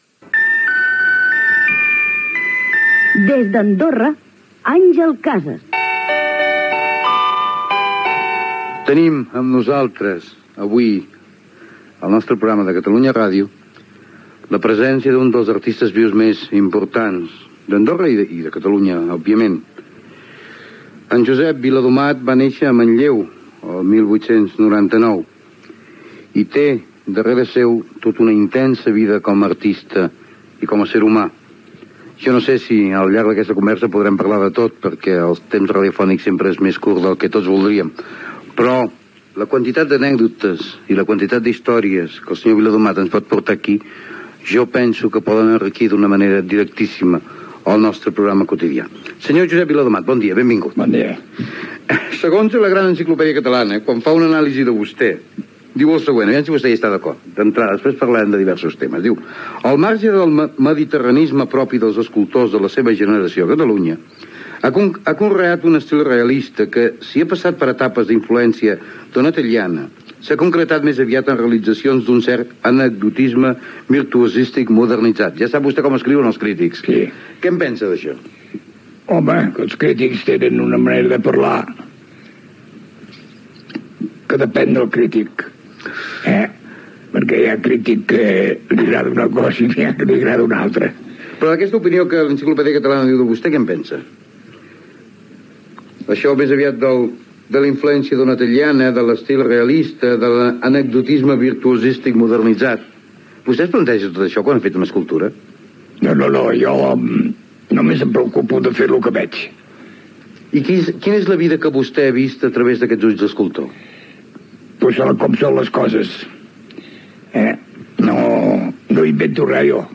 Indicatiu del programa, presentació i entrevista a l'escultor Josep Viladomat i Massanes
Entreteniment